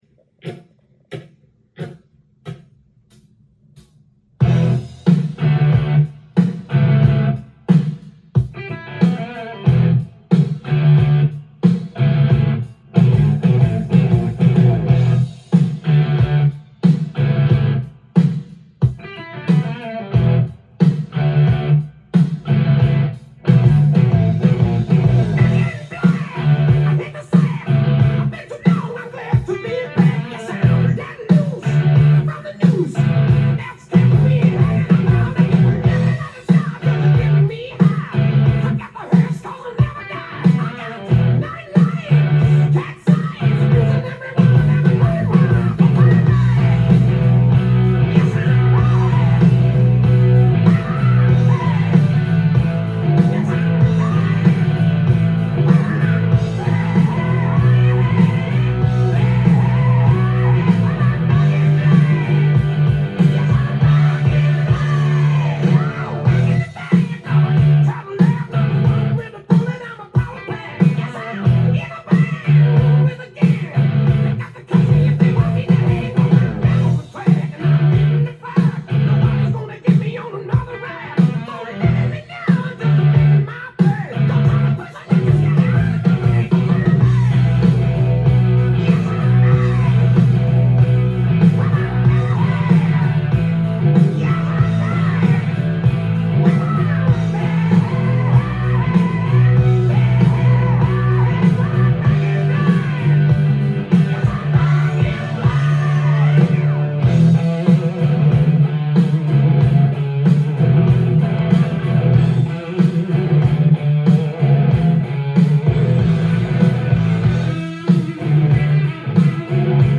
ジャンル：HARD-ROCK
店頭で録音した音源の為、多少の外部音や音質の悪さはございますが、サンプルとしてご視聴ください。
音が稀にチリ・プツ出る程度